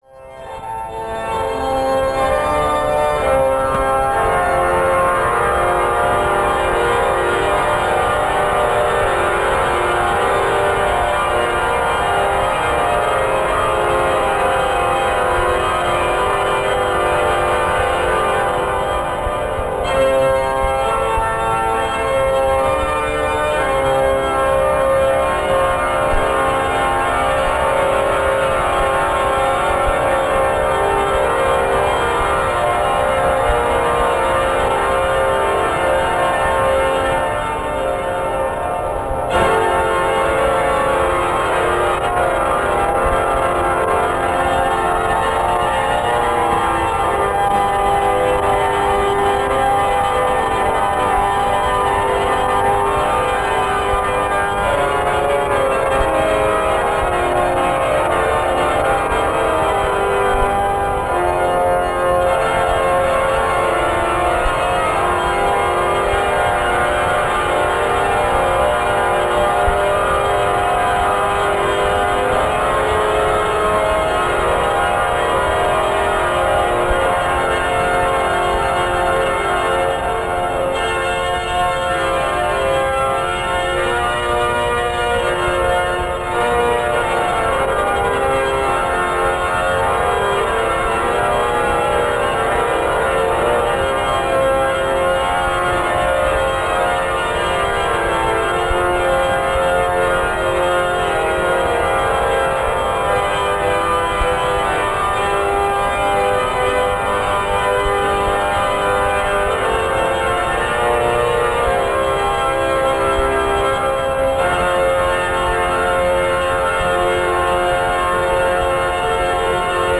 2.06  -Organo della Basilica di San Pietro in Roma.
N.D.R.  La registrazione è stata eseguita con mezzi di fortuna, ci auguriamo di rivederlo, risentirlo e registrarlo, presto a Buja.